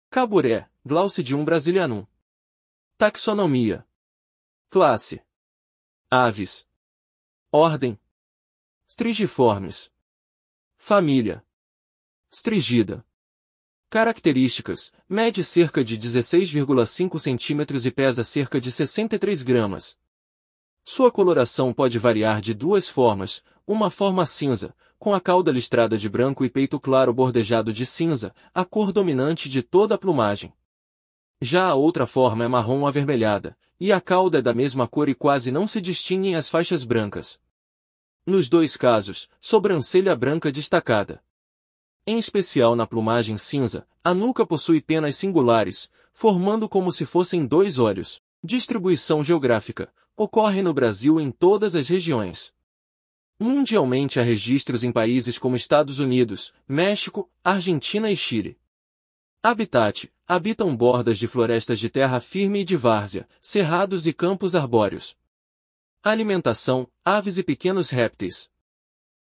Caburé